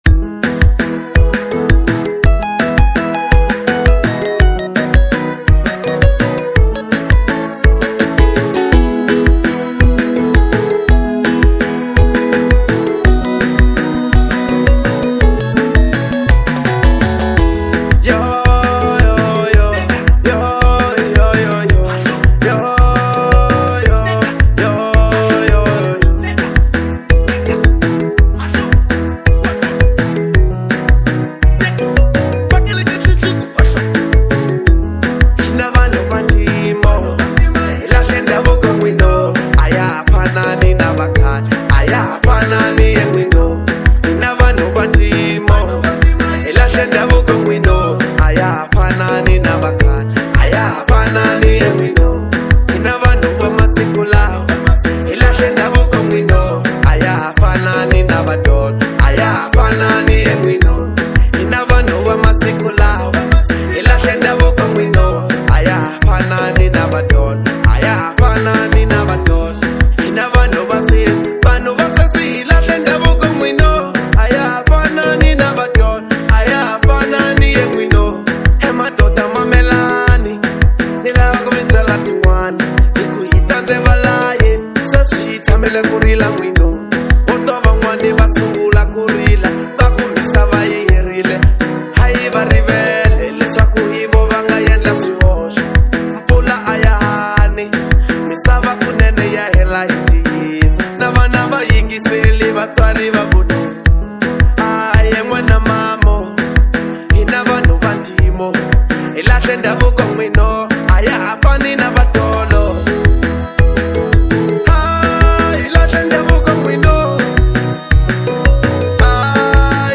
04:59 Genre : Marrabenta Size